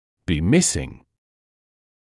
[biː ‘mɪsɪŋ][биː ‘мисин]отсутствовать